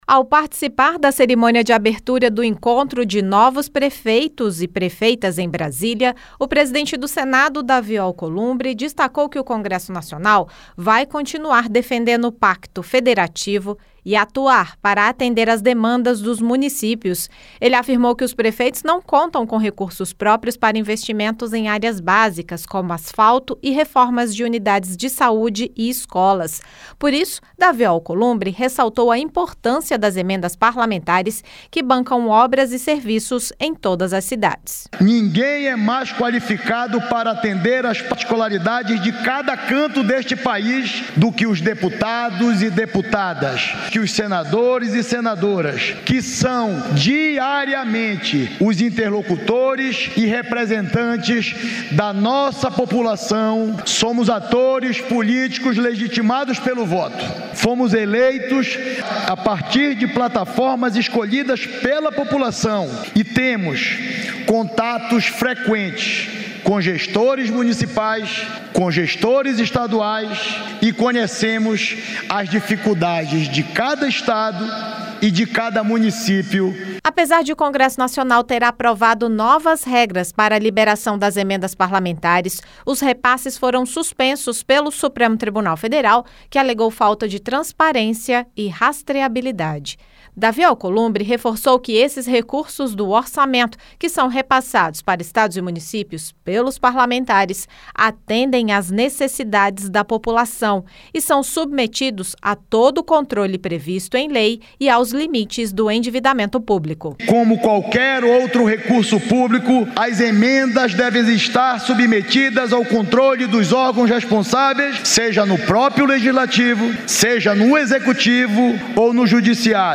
Em evento com prefeitos que assumiram o mandato em janeiro, o presidente do Senado, Davi Alcolumbre, defendeu a importância das emendas parlamentares. Ele destacou que senadores e deputados conhecem a realidade das cidades, por isso, têm legitimidade para fazer os repasses do Orçamento Geral da União.